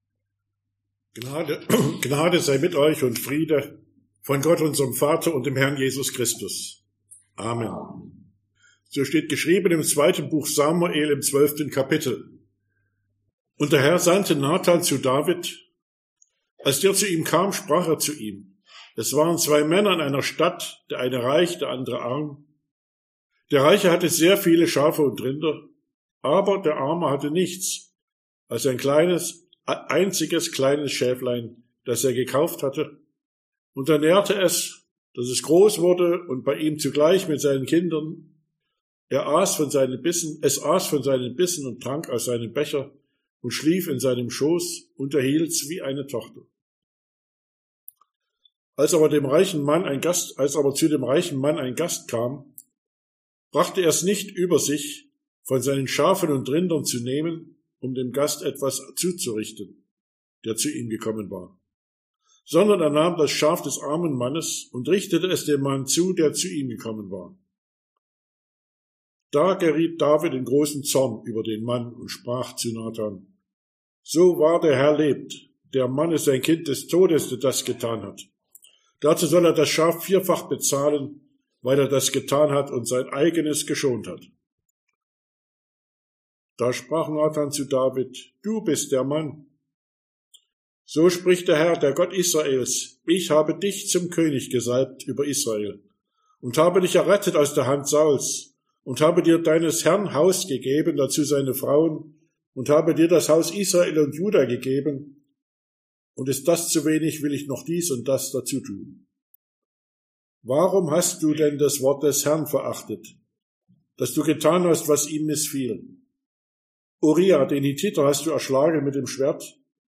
Quasimodogeniti Passage: 2. Samuel 12, 1-14 Verkündigungsart: Predigt « Ostermontag 2023 Miserikoridas Domini („Die Güte des Herrn“) 2023 »